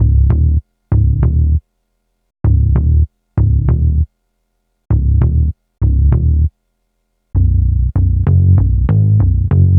Rock star - Sub Bass.wav